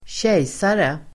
Ladda ner uttalet
kejsare substantiv, emperor Uttal: [²tj'ej:sare] Böjningar: kejsaren, kejsare, kejsarna Synonymer: furste, härskare, monark, tsar Definition: (titel för) en viss monark Sammansättningar: kejsar|döme (empire)